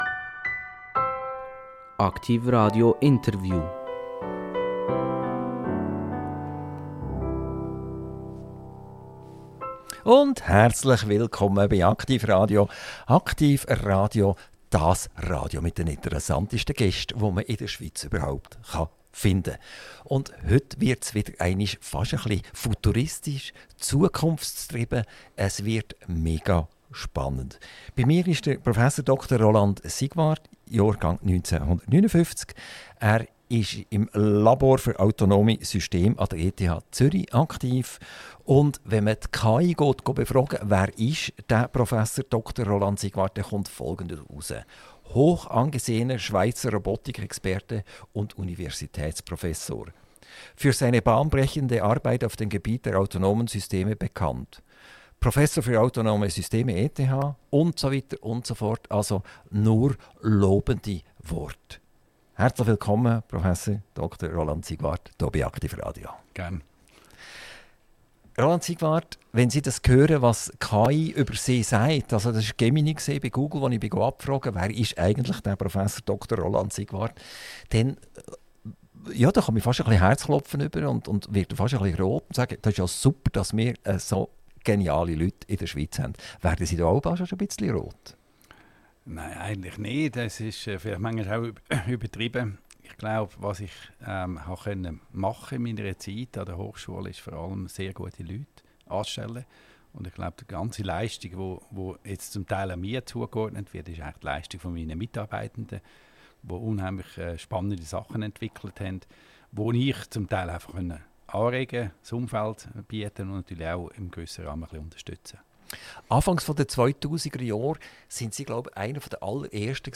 Gast: Prof. Dr. Roland Siegwart Gastgeber